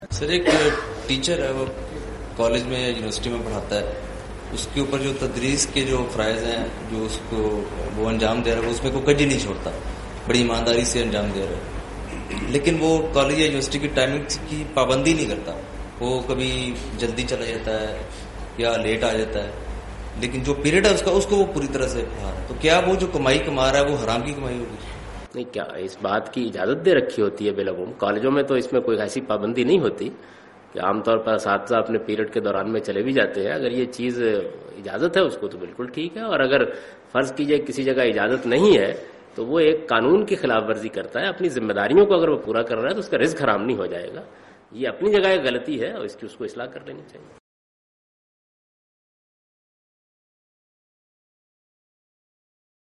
Javed Ahmad Ghamidi responds to the question' A teacher steals time from his duty hours-Is his income leigitimate?
جاوید احمد غامدی اس سوال کا جواب دیتے ہوئے کہ ایک "استاد جو اپنی زمہ داری دیانتداری سے نہیں انجام دے رہا کیا اس کی آمدنی جائز ہےکیا؟"